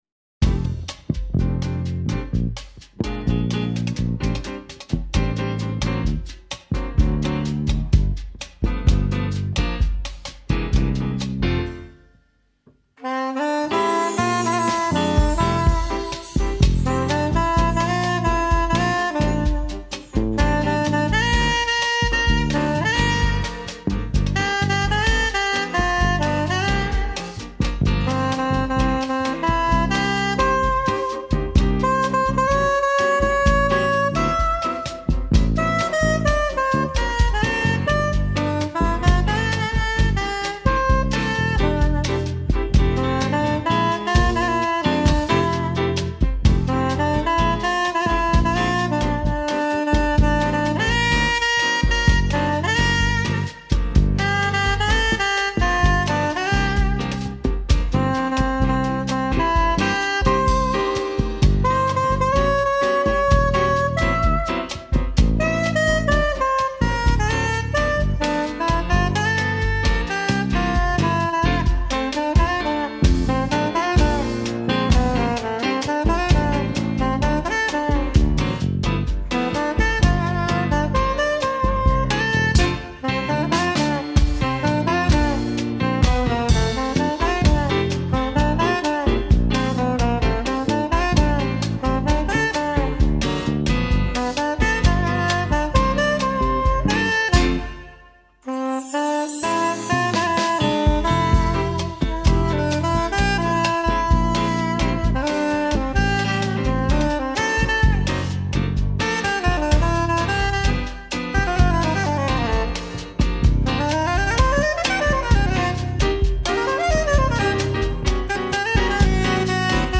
1320   03:17:00   Faixa:     Jazz